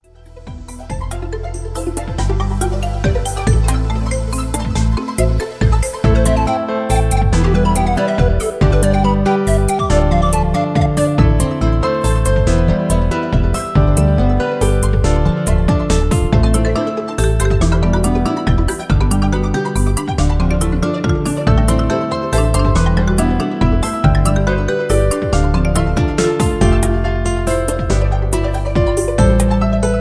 Dramatic Electro Melodic Chillout